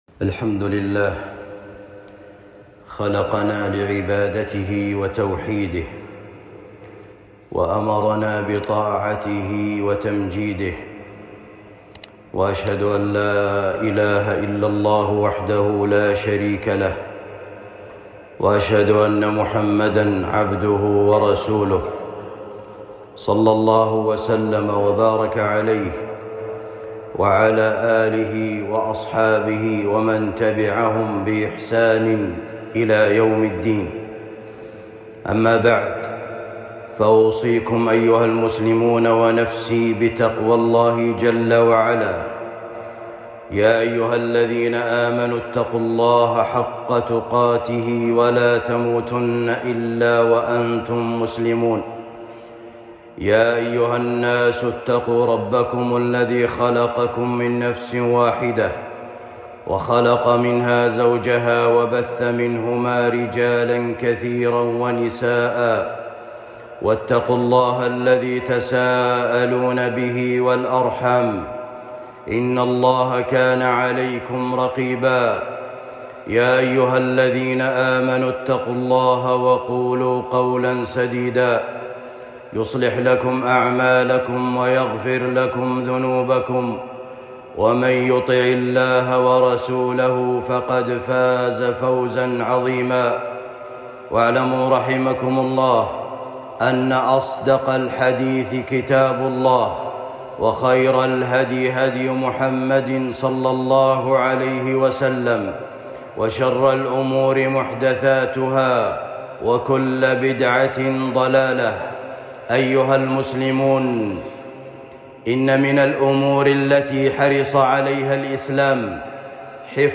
الخطب